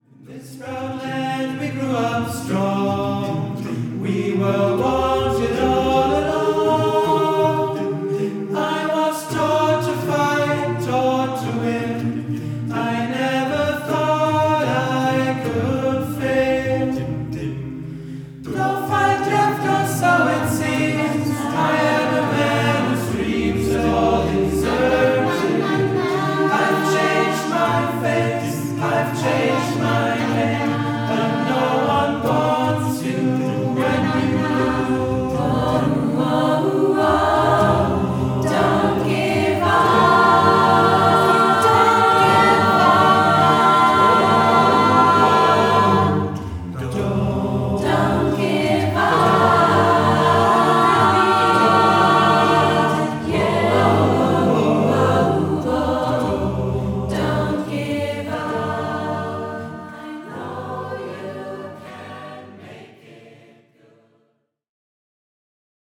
• Dem Tonstudio Tessmar in Hannover